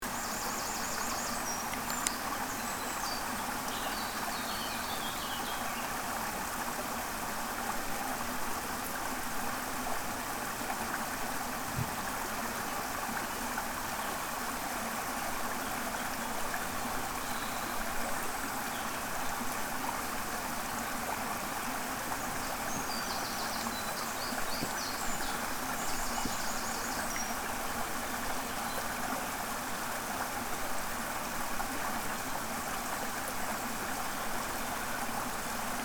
Small Waterfall With Birds Chirping Sound Effect Download: Instant Soundboard Button